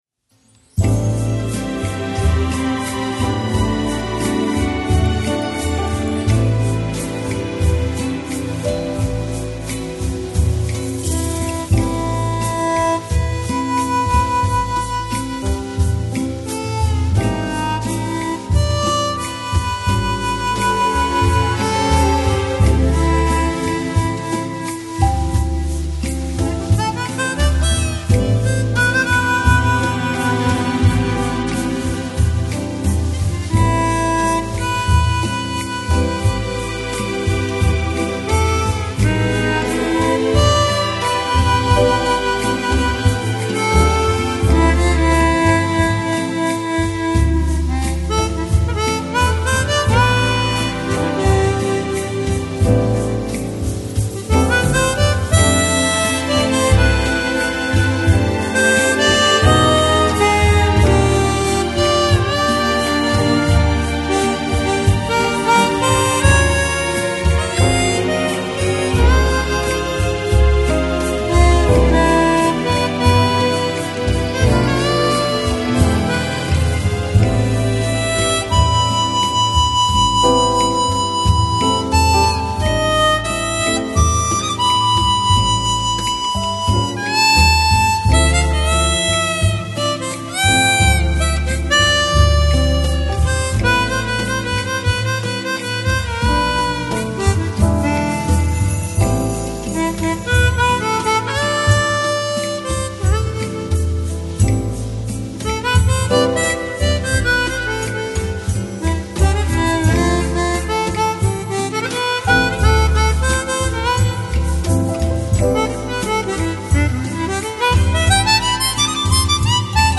Pop Jazz, Nu Jazz Издание